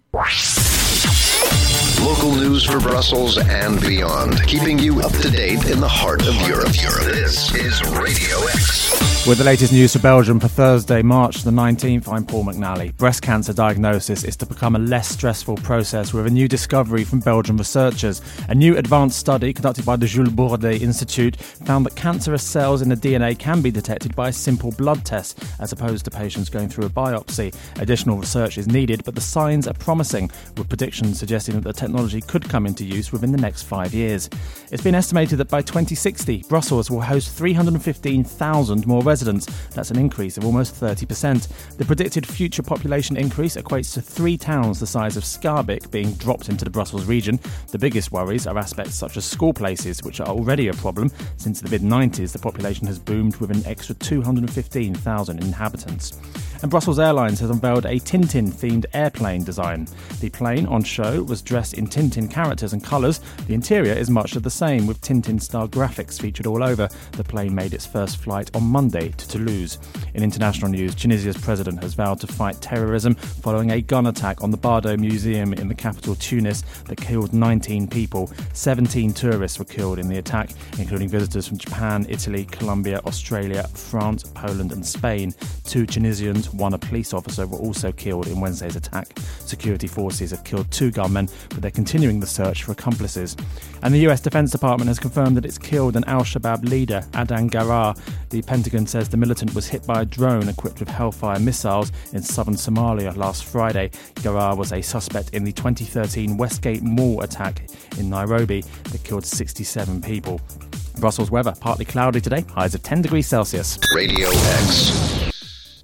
Breast cancer diagnosis breakthrough - Brussels population to soar - Tunisia museum shooting. Latest headlines for Radio X in Brussels.